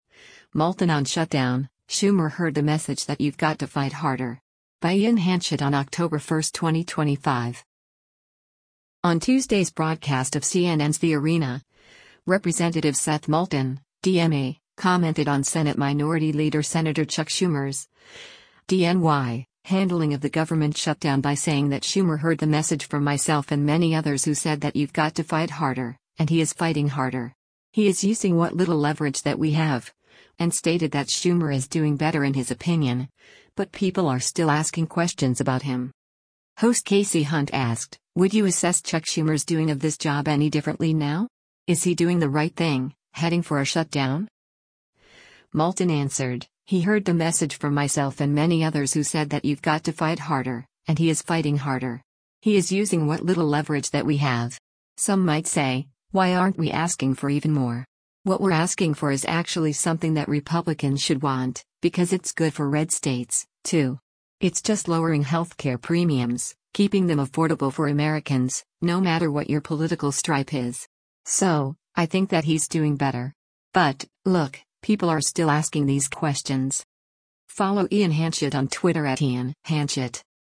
On Tuesday’s broadcast of CNN’s “The Arena,” Rep. Seth Moulton (D-MA) commented on Senate Minority Leader Sen. Chuck Schumer’s (D-NY) handling of the government shutdown by saying that Schumer “heard the message from myself and many others who said that you’ve got to fight harder, and he is fighting harder. He is using what little leverage that we have.”